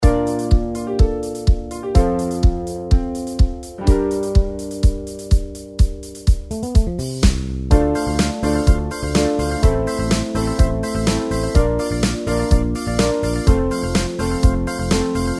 הטמפו של הפלייבק: 125 BPM
דוגמה מתוך הפלייבק: